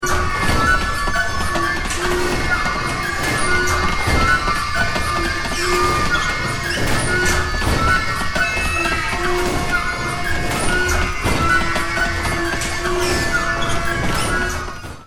Another complex montage then subtly crossfades in.